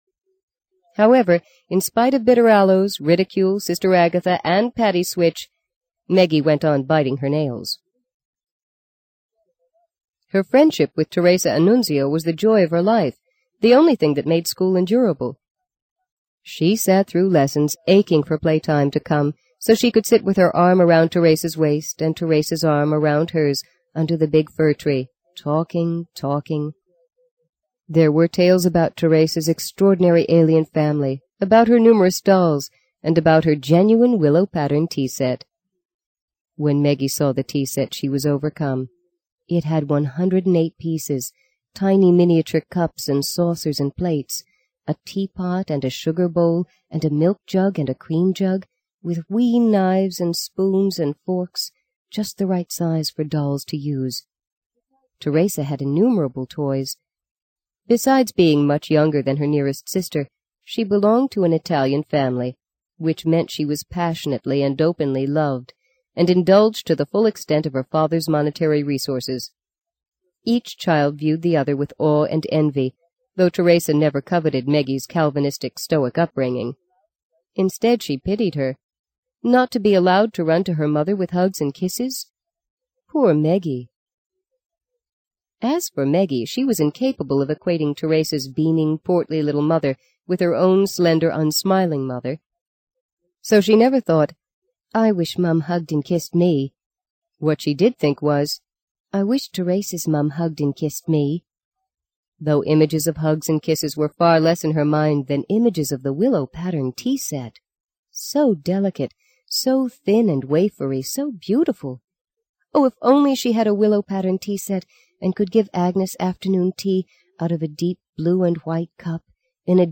在线英语听力室【荆棘鸟】第二章 17的听力文件下载,荆棘鸟—双语有声读物—听力教程—英语听力—在线英语听力室